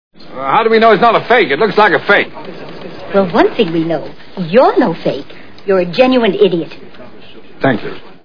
North by Northwest Movie Sound Bites